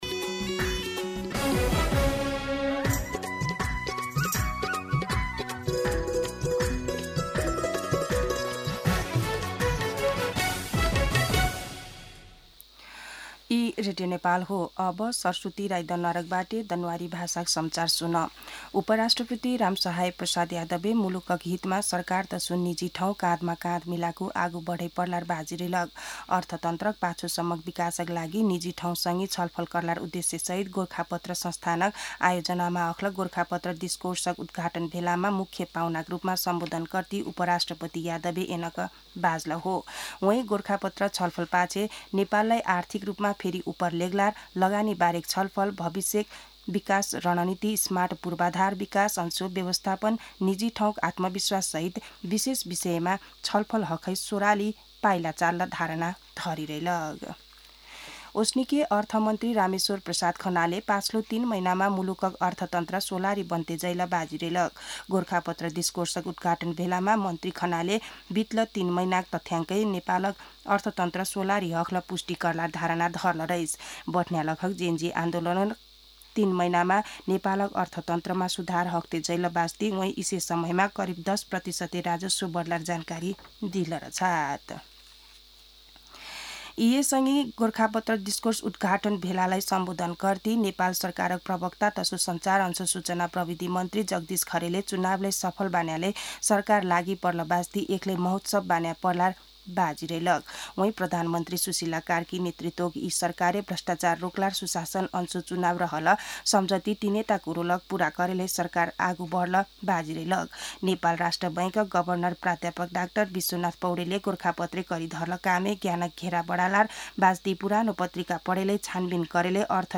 दनुवार भाषामा समाचार : ३ पुष , २०८२
Danuwar-News-9-3.mp3